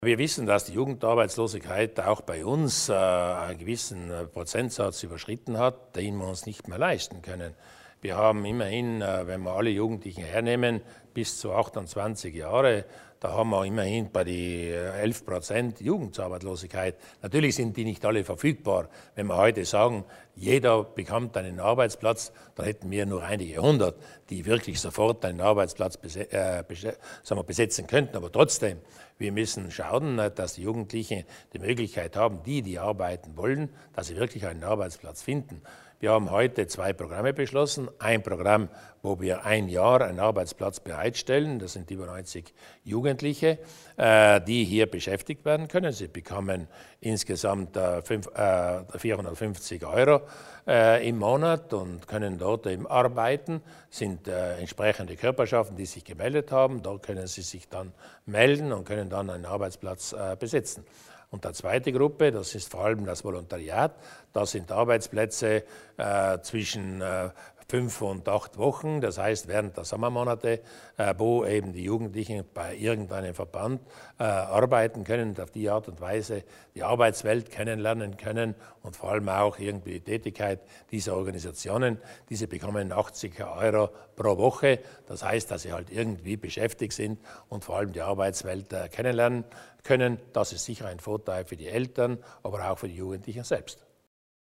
Landeshauptmann Durnwalder erläutert die Projekte gegen Jugendarbeitslosigkeit